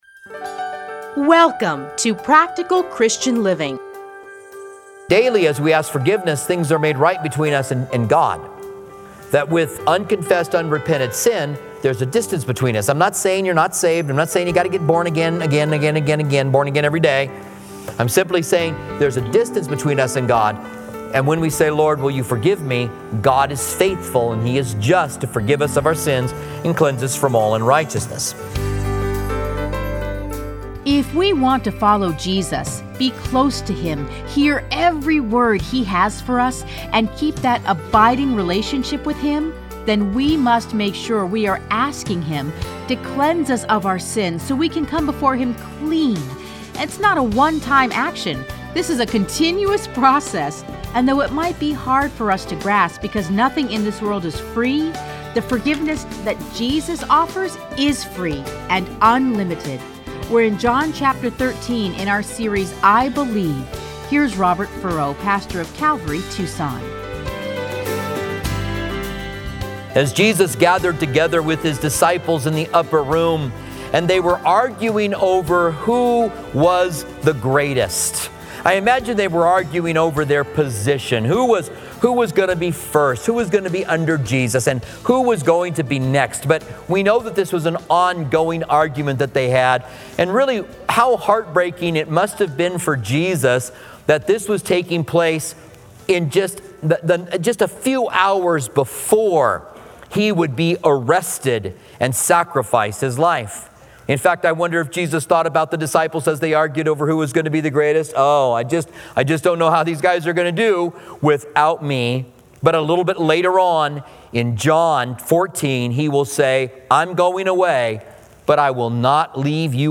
Listen to a teaching from John 13:1-17.